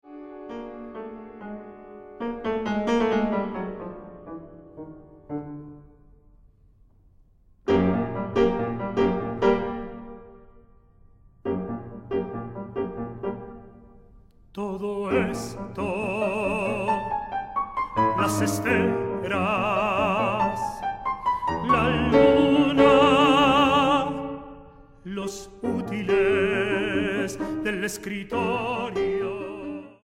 para voz y piano.